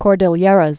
(kôrdĭl-yĕrəz, -dē-yĕräs)